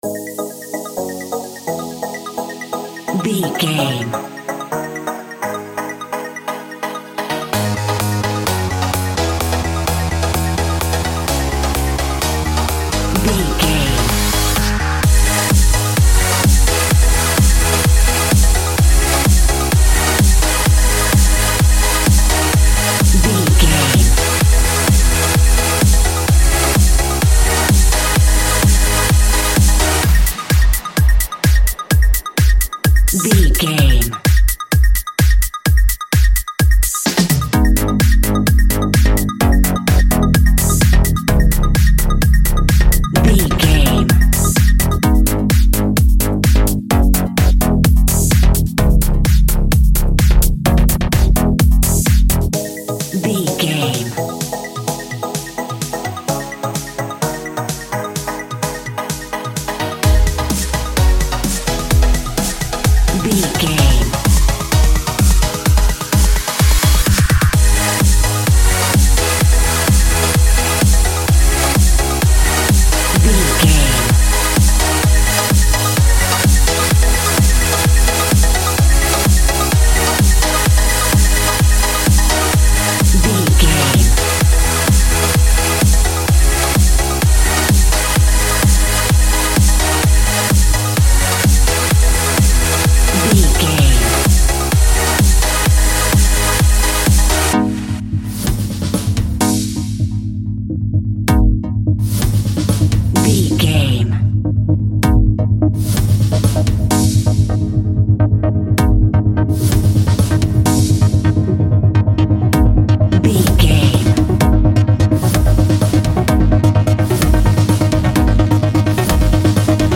Aeolian/Minor
G#
Fast
uplifting
futuristic
driving
energetic
cheerful/happy
repetitive
bouncy
synthesiser
drum machine
house
electro dance
techno
trance
instrumentals
synth leads
synth bass
uptempo